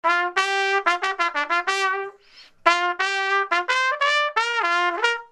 Trompette.mp3